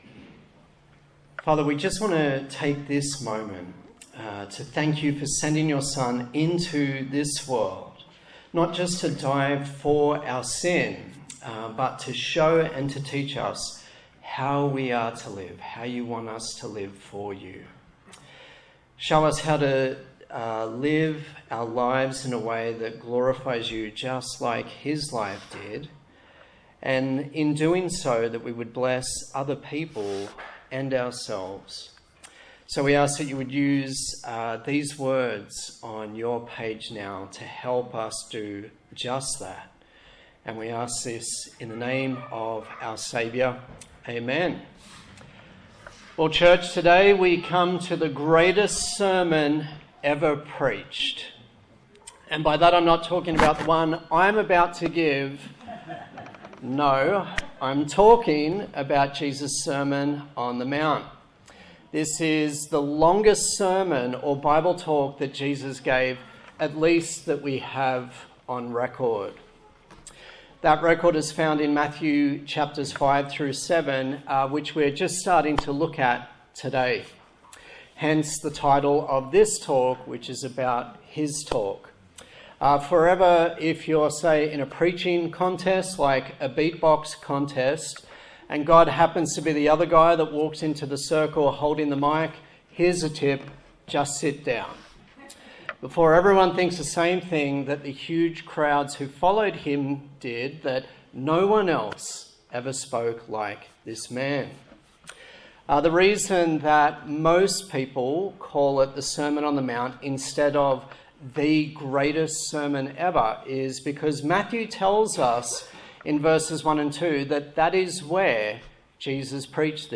Passage: Matthew 5:1-16 Service Type: Sunday Morning